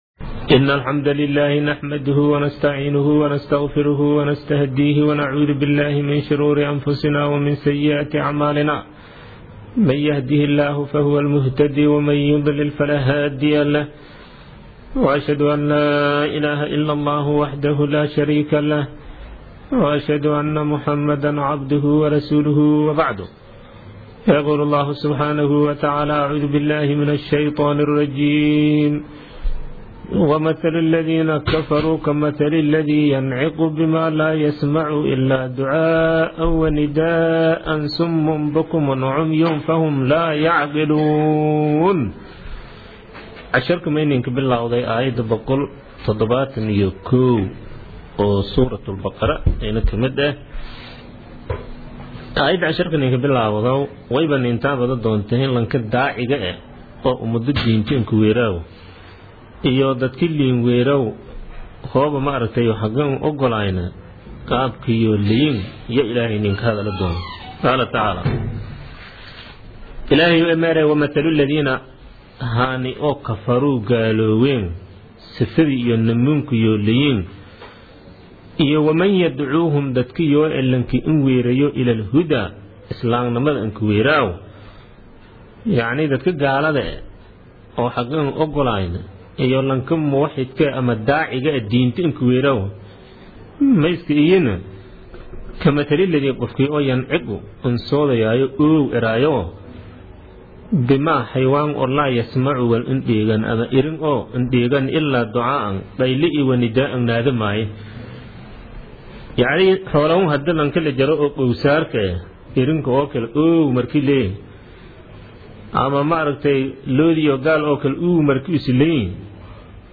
Casharka Tafsiirka Maay 21aad